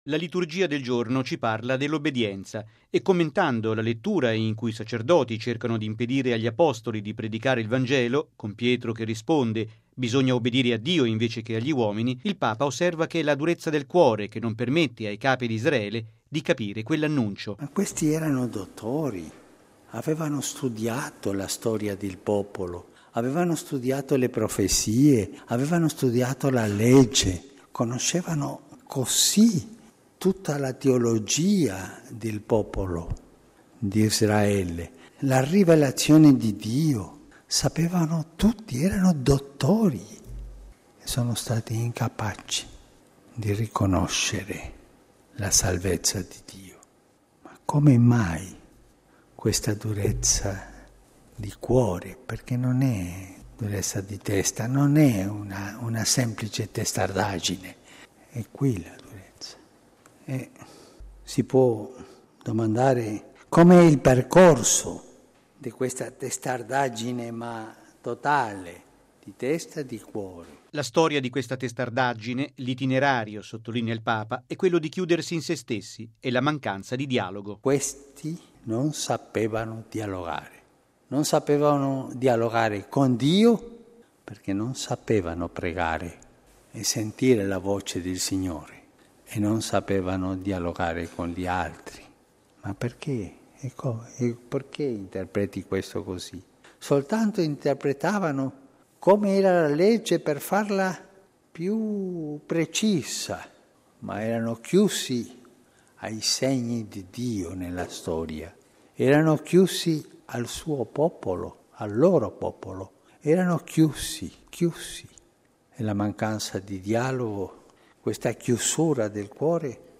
Chi non sa dialogare non obbedisce a Dio e vuole far tacere quanti predicano la novità di Dio: è quanto ha affermato il Papa nella Messa del mattino celebrata nella Cappella di Casa Santa Marta.